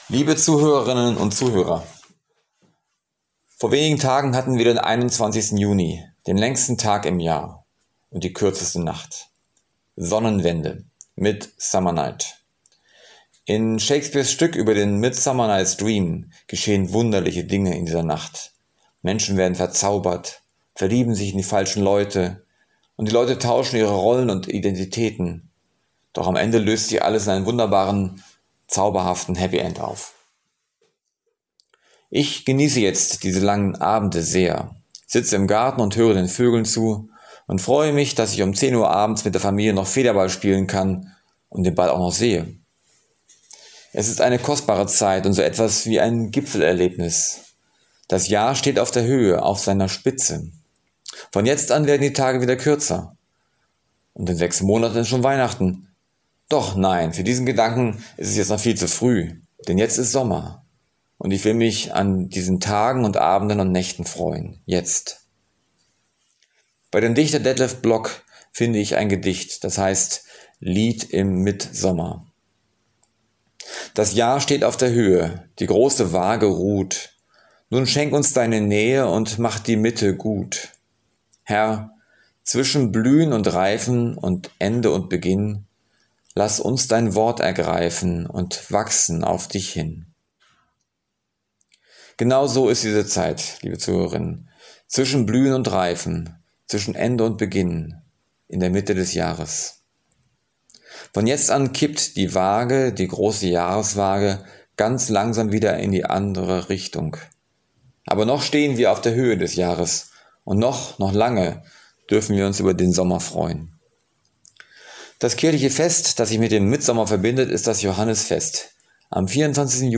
Andacht zum 28.6.